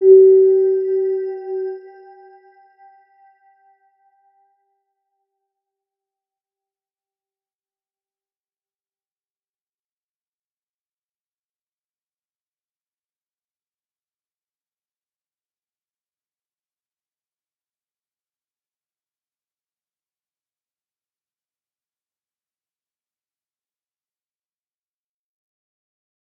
Round-Bell-G4-p.wav